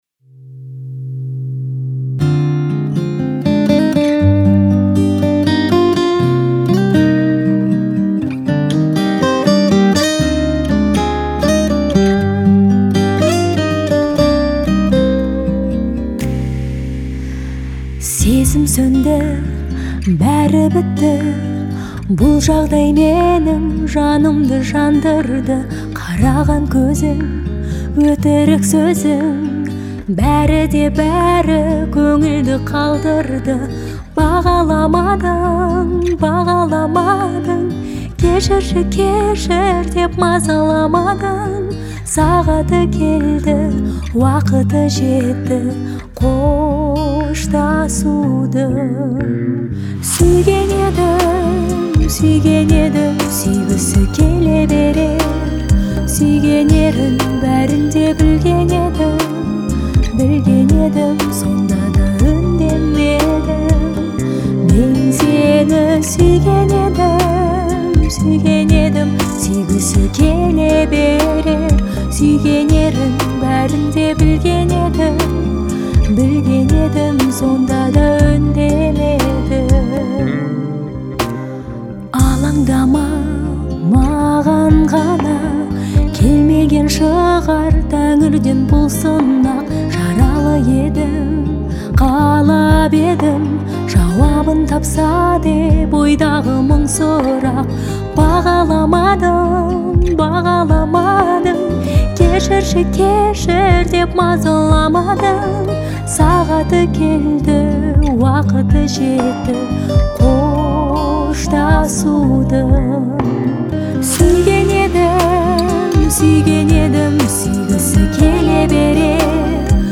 выделяется своим нежным вокалом и искренним исполнением